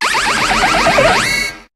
Cri de Magnézone dans Pokémon HOME.